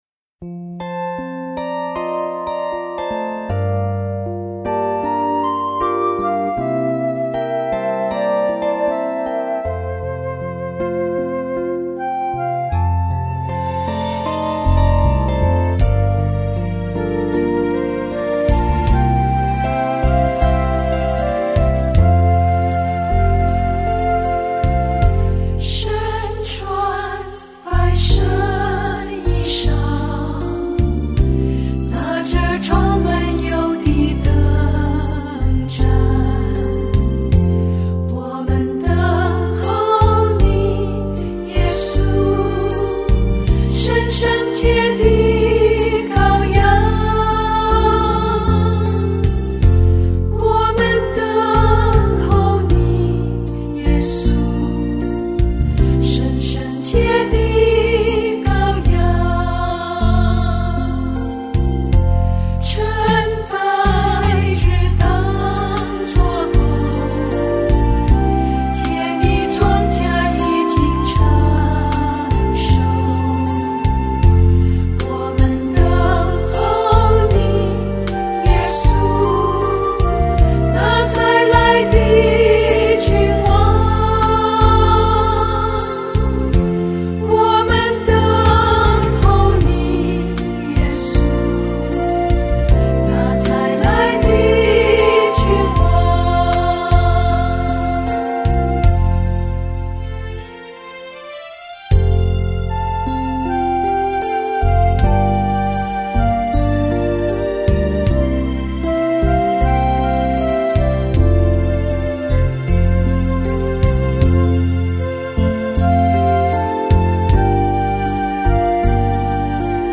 F調4/4